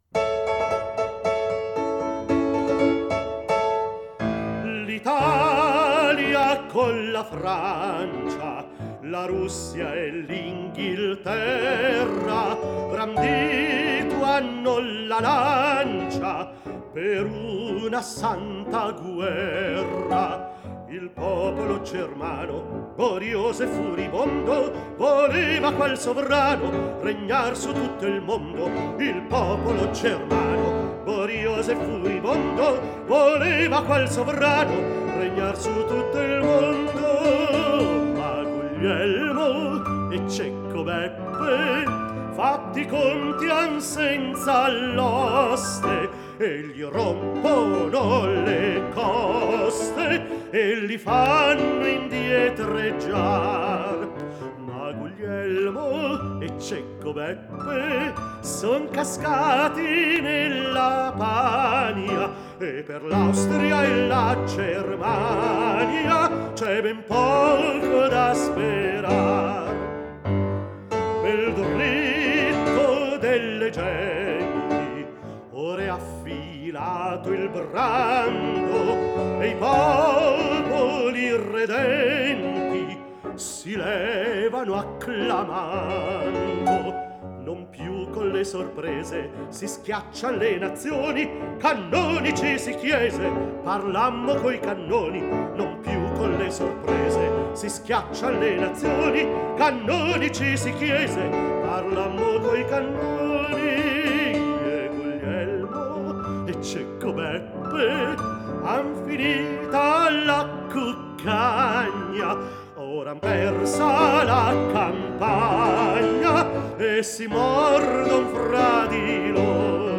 tenore
pianoforte